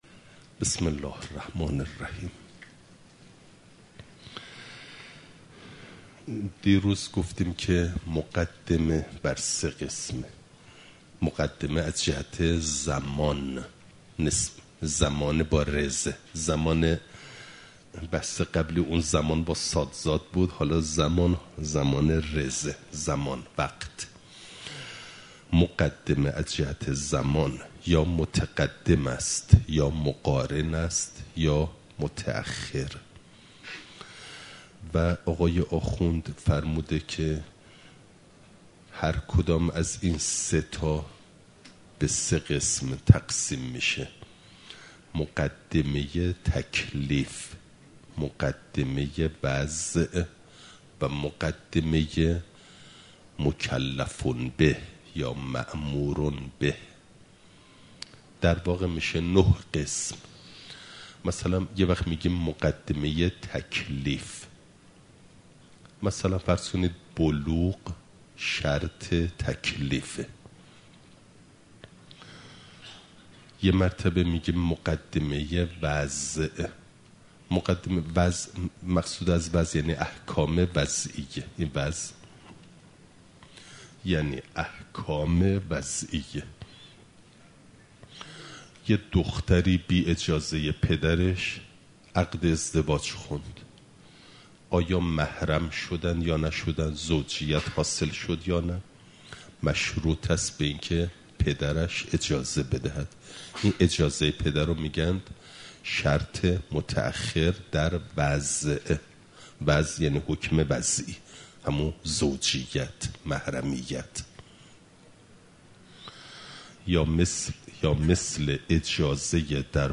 خارج اصول، مقدمه واجب (جلسه ۱۰) – دروس استاد